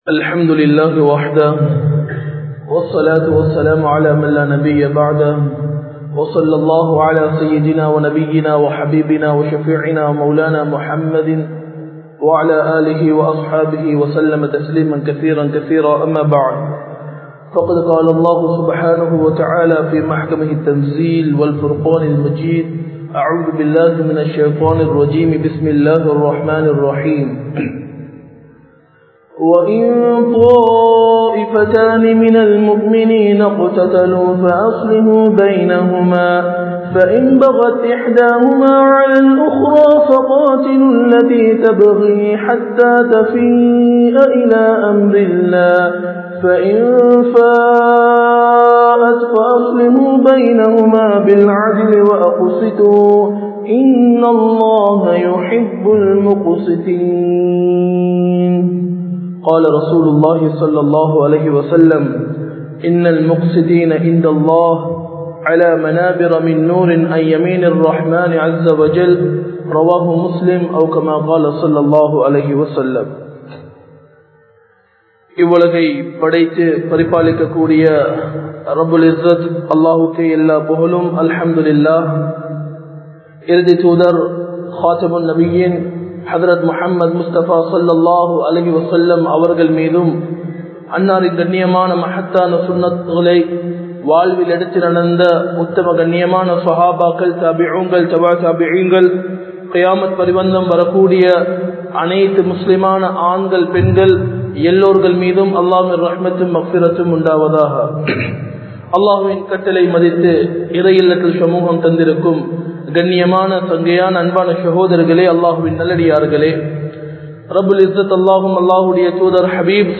Neethamaaha Nadappoam (நீதமாக நடப்போம்) | Audio Bayans | All Ceylon Muslim Youth Community | Addalaichenai
Majma Ul Khairah Jumua Masjith (Nimal Road)